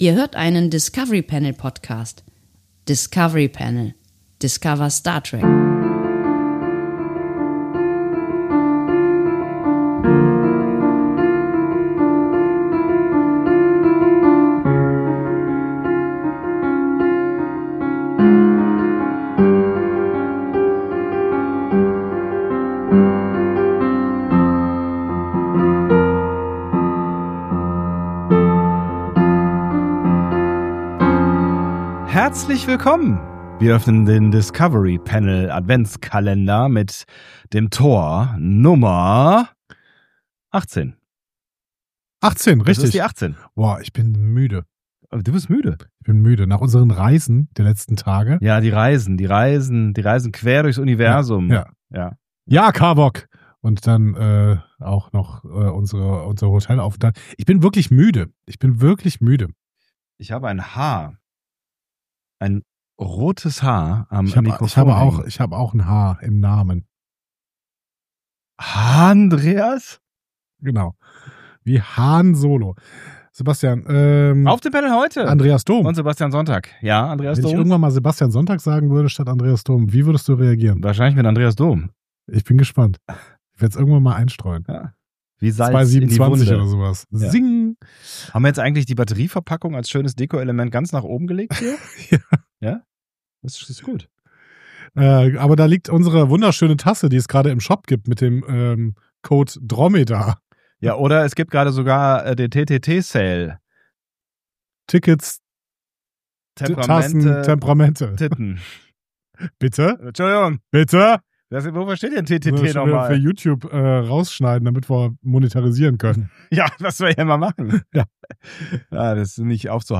Wir sind NICHT im Studio.
Im Hotelzimmer.